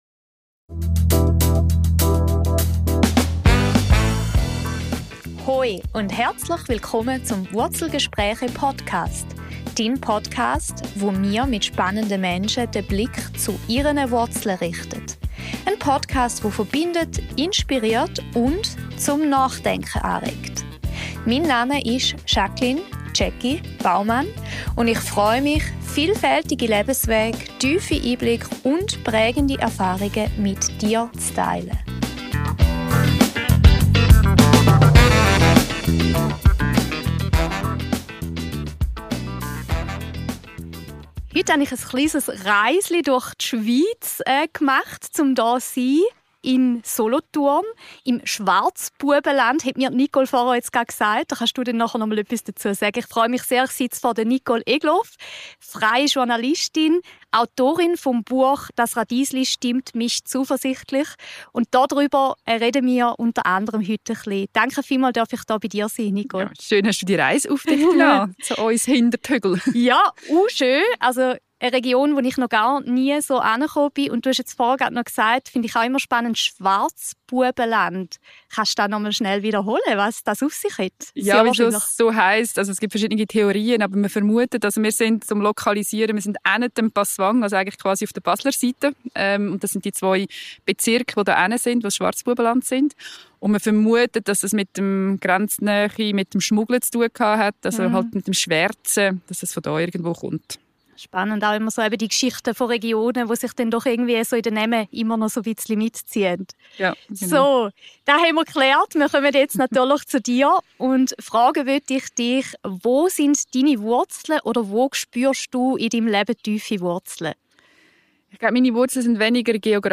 Wir sprechen über den Schnittpunkt zwischen Landwirtschaft, Nachhaltigkeit und Konsum, über die Verantwortung, die wir als Konsumierende tragen, und darüber, wie Vielfalt und Sensibilisierung uns helfen können, Lebensräume zu erhalten und die Zukunft unserer Lebensmittel neu zu gestalten. Ein Gespräch voller Zuversicht, das Mut macht, genauer hinzuschauen – und kleine Schritte für eine nachhaltige Ernährungszukunft zu gehen.